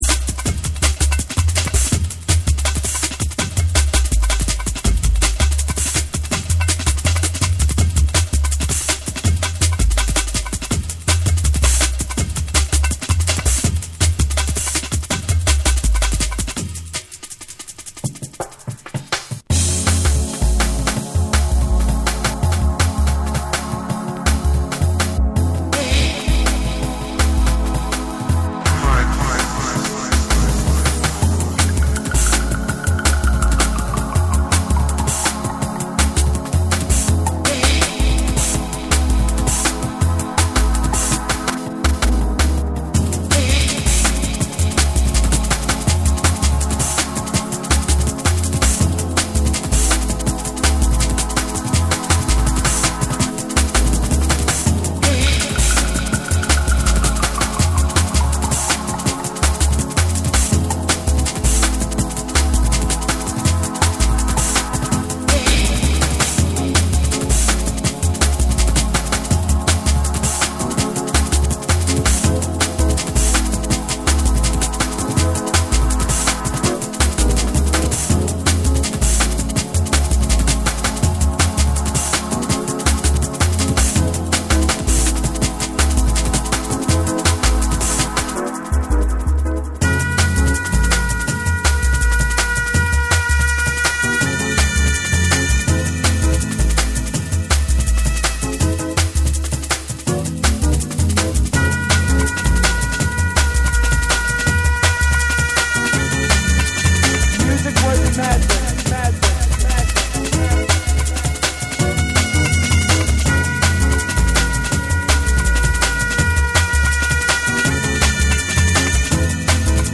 ジャズ要素が強調されたアレンジと、繊細パーカッションも絡むドラムパターンが調和しながら展開する
JUNGLE & DRUM'N BASS